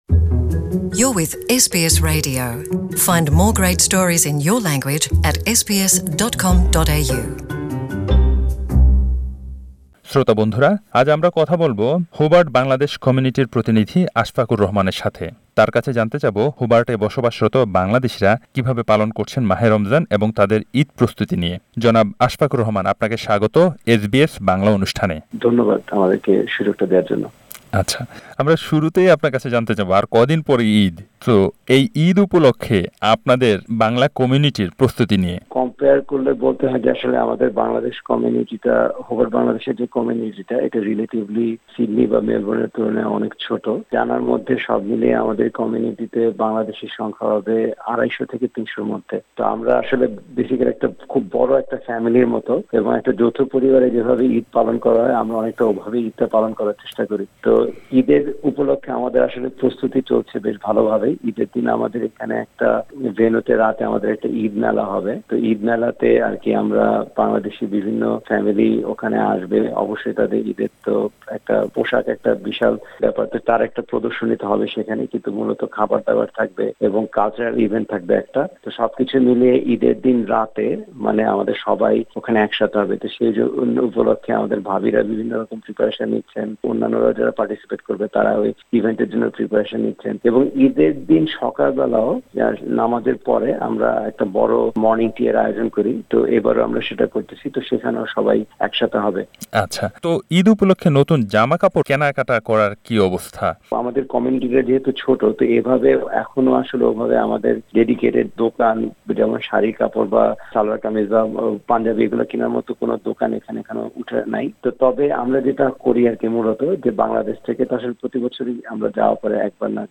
পুরো কথোপকথন শুনতে উপরের লিংকে ক্লিক করুন।